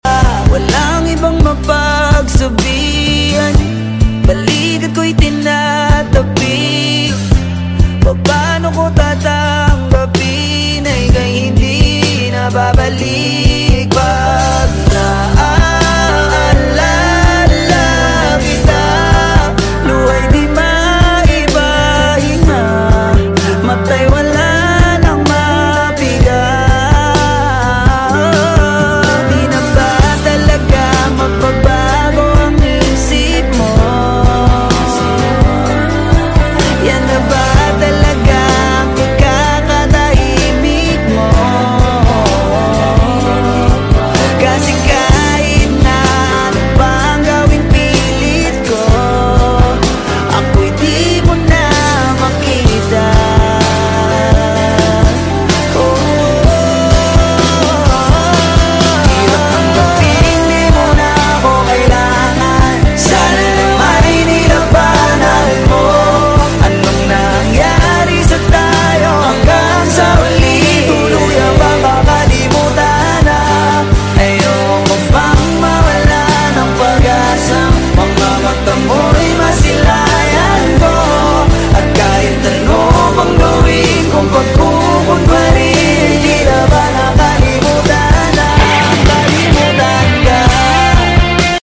heartfelt song